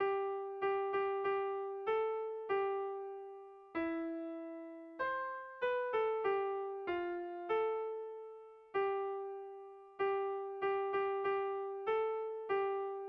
Haurrentzakoa
Zortziko txikia (hg) / Lau puntuko txikia (ip)
A1BA2